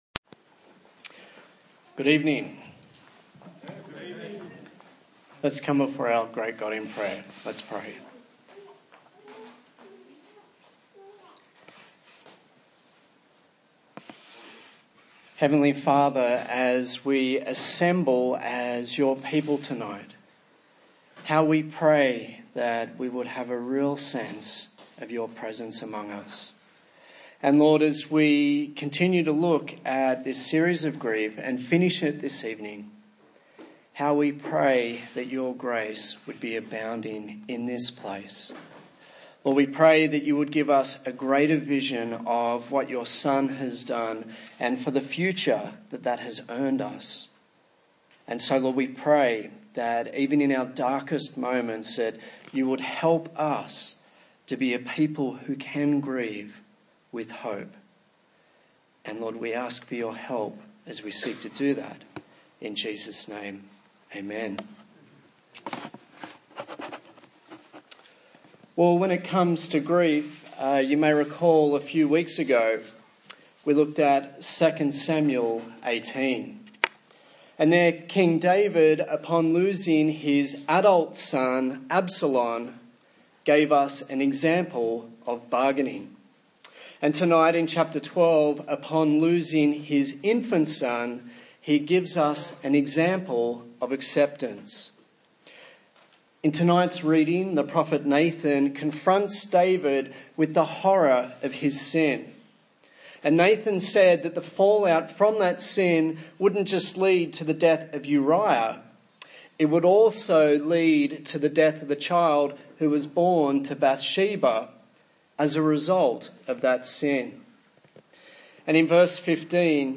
A sermon in the series on Grief – Redeeming Broken Glass